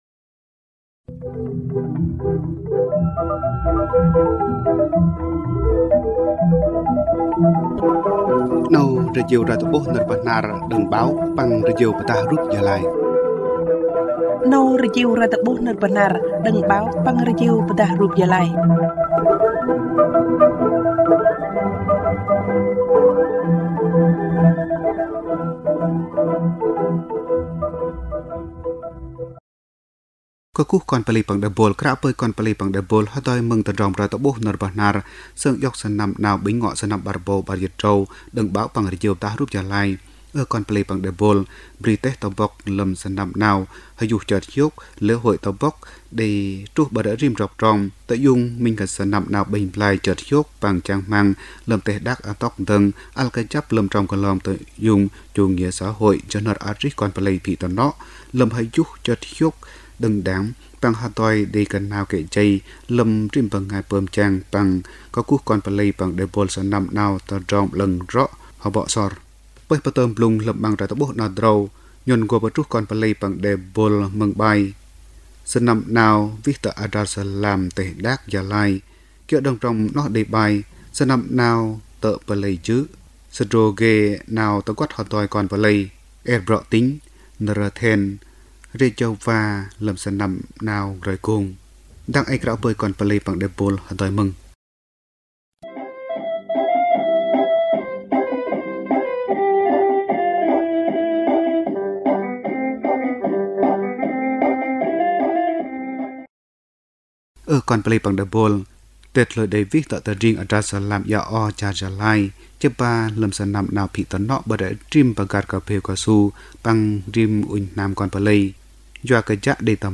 Thời sự PT tiếng Bahnar